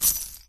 UI_OpenShop.ogg